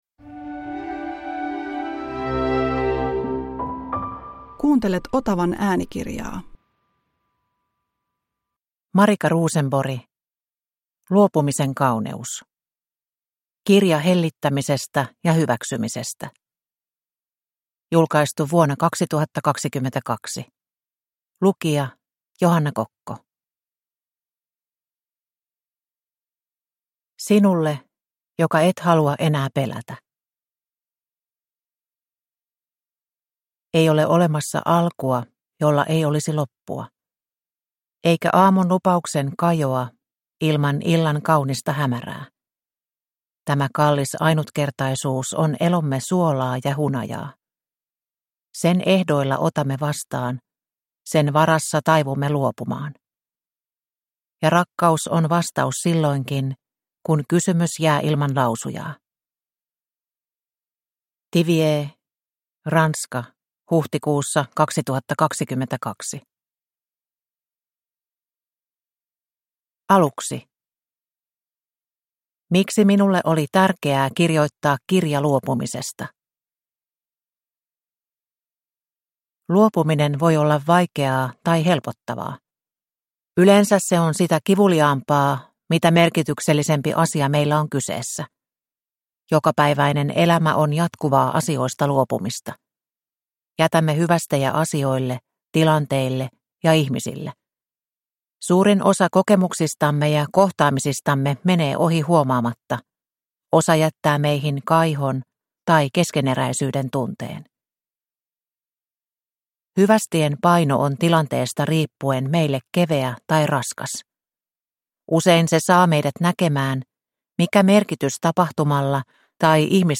Luopumisen kauneus – Ljudbok – Laddas ner